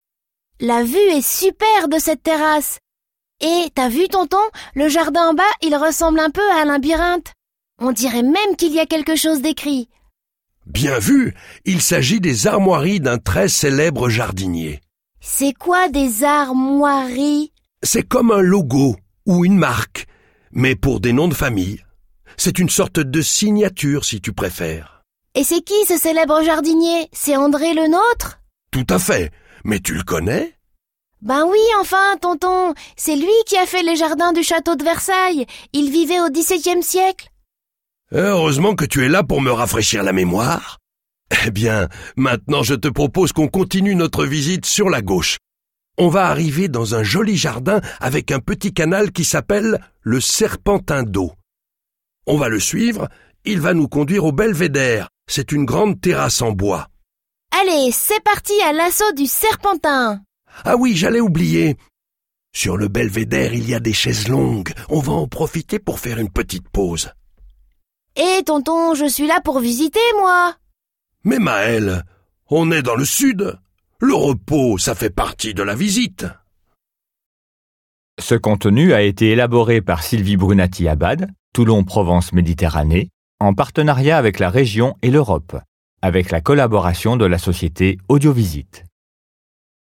Audio-guide La Valette-du-Var